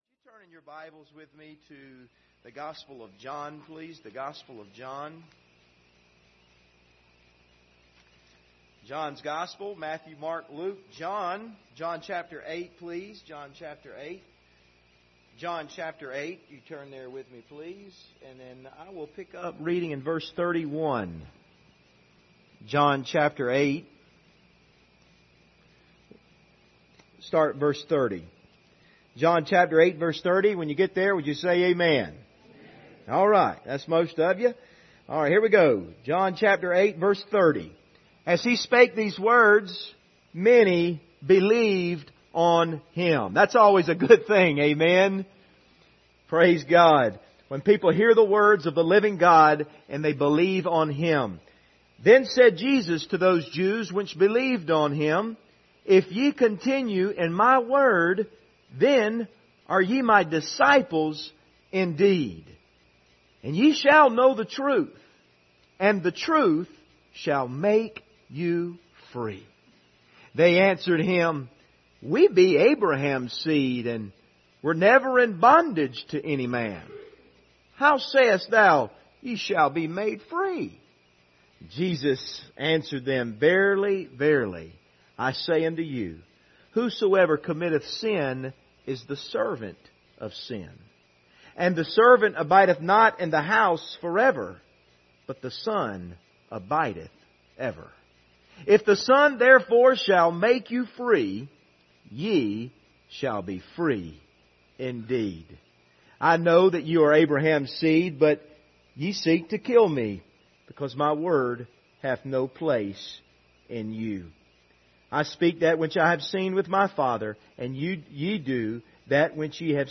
General Passage: John 8:30-38 Service Type: Sunday Evening « Emotionally Healthy Discipleship